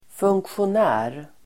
Ladda ner uttalet
Uttal: [fungksjon'ä:r]